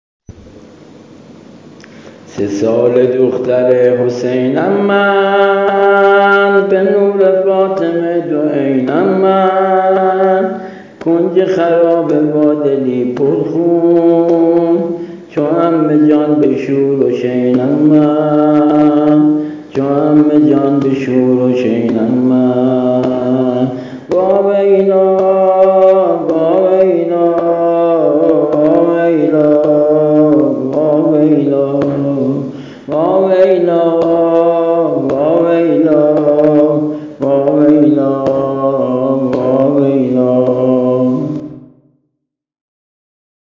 ◾نوحه سینه زنی حضرت رقیه